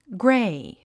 gréi グレェイ